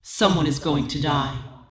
Subject description: Perhaps an extremely rare basic main battle tank with a female voice   Reply with quote  Mark this post and the followings unread
I am not a woman, this is using AI technology to replace my vocals with AI tones.